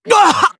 Kibera-Vox_Damage_jp_03.wav